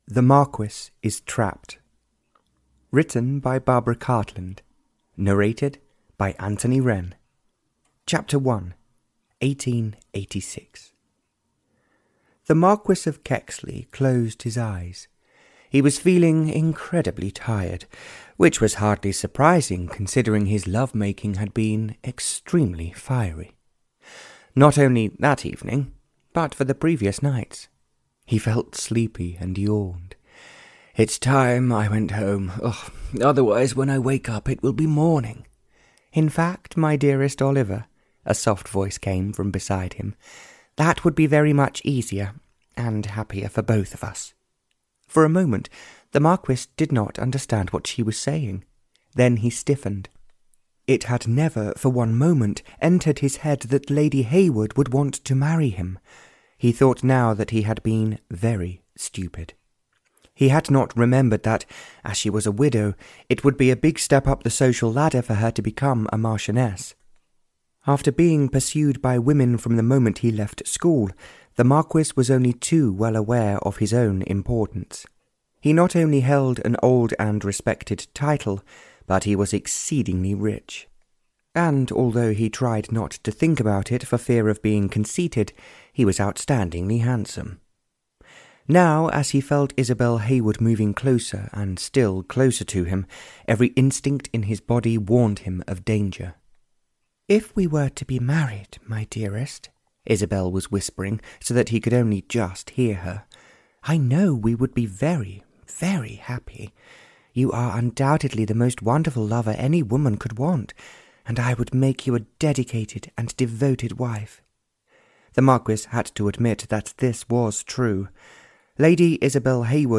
The Marquis is Trapped (Barbara Cartland’s Pink Collection 68) (EN) audiokniha
Ukázka z knihy